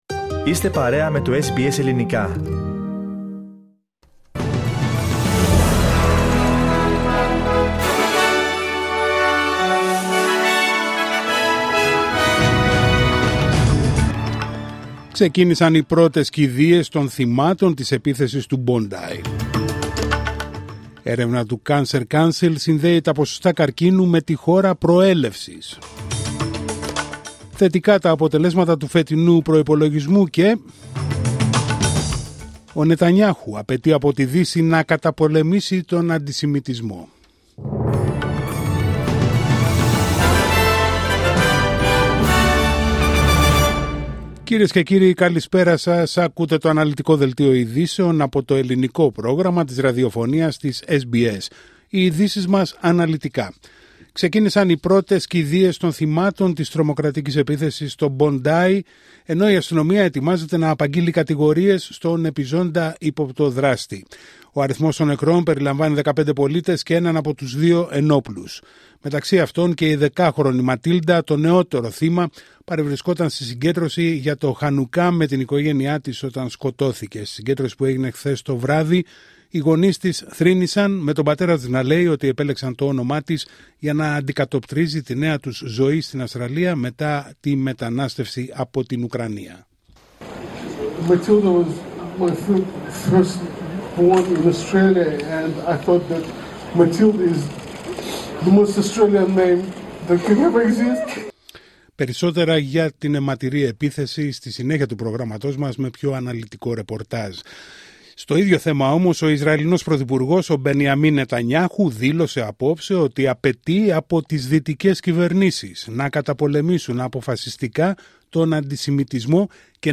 Δελτίο ειδήσεων Τετάρτη 17 Δεκ. 25